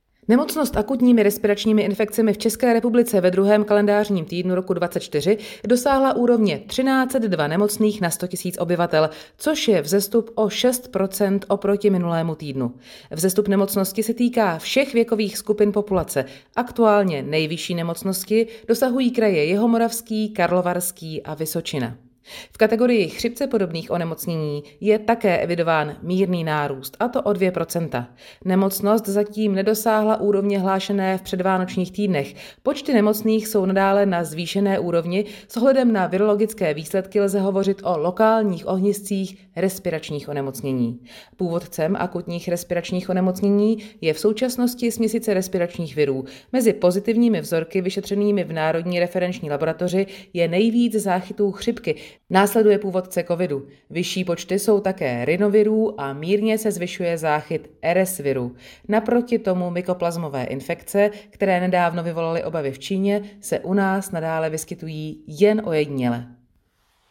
Komentář ve zvuku - ARI a ILI 2. týden 24